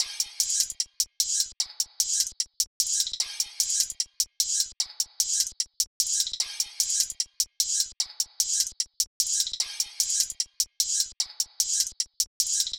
drumloop 1 (150 bpm).wav